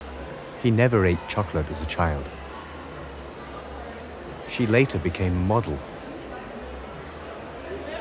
Denoiser OFF
UC3_babble.wav